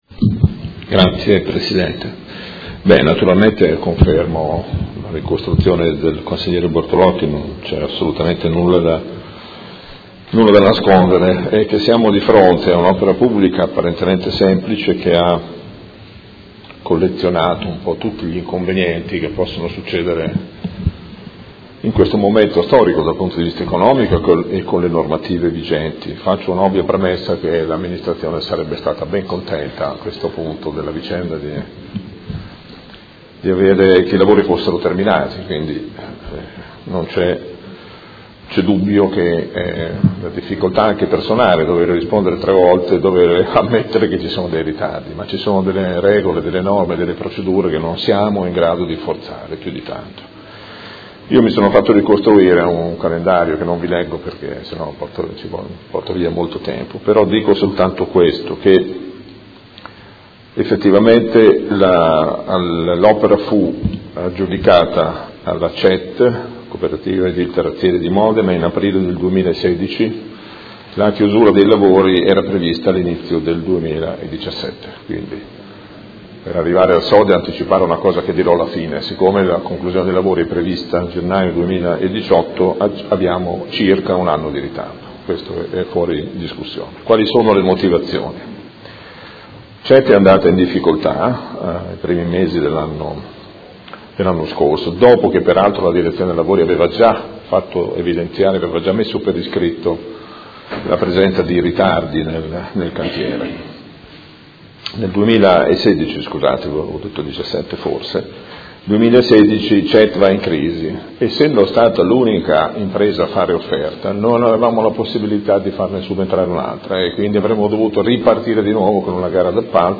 Seduta del 30/11/2017. Risponde a interrogazione del Gruppo M5S avente per oggetto: Completamento sottopasso ferroviario tra zona Crocetta (ex BenFra) e Via Scaglietti (ex Acciaierie) a fianco del cavalcavia Ciro Menotti